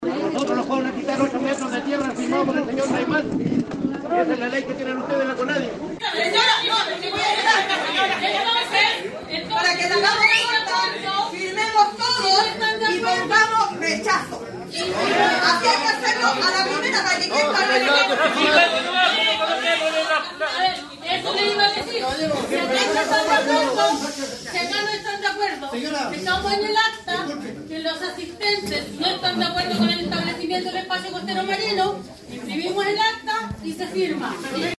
Los tensos momentos se vivieron en la sede de los pescadores artesanales de la localidad de Metri en la Carretera Austral, Puerto Montt.